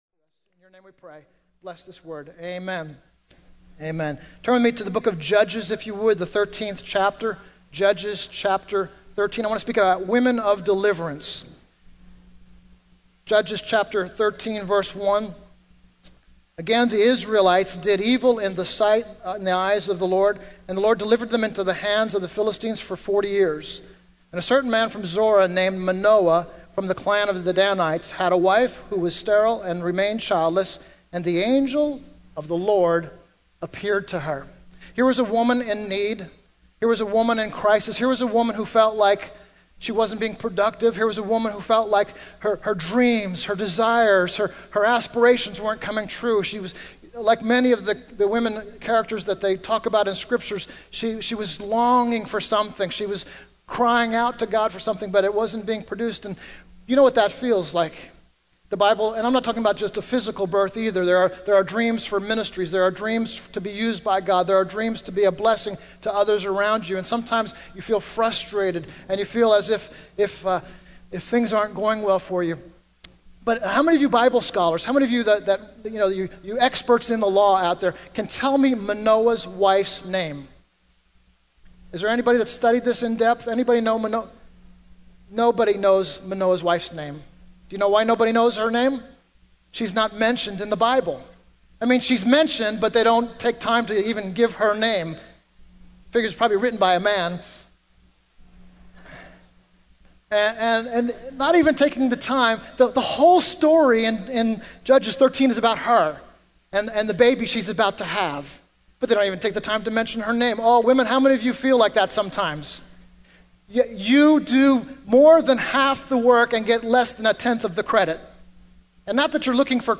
In this sermon, the preacher discusses the story of Manoa's wife from the book of Judges.